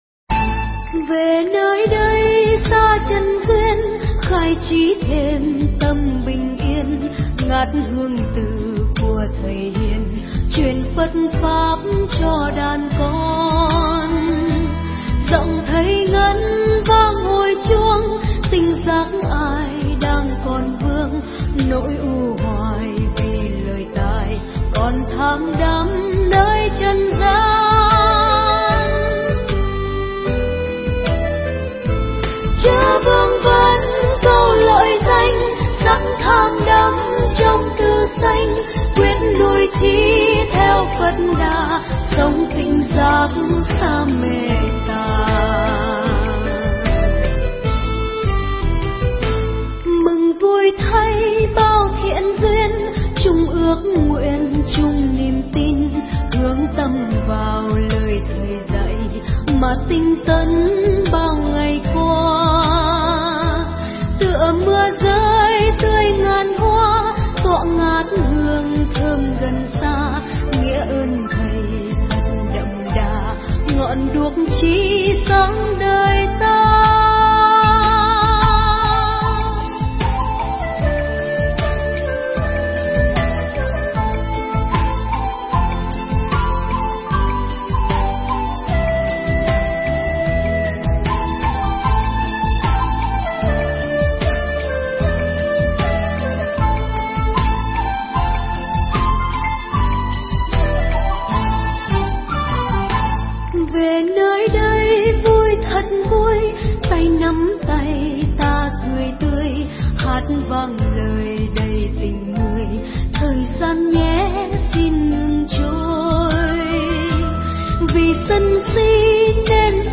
Tải mp3 Pháp Thoại Nhẫn Người Hàng Xóm – Đại Đức Thích Pháp Hòa thuyết giảng tại Như Lai Thiền Viện, khóa tu an lạc, ngày 7 tháng 7 năm 2013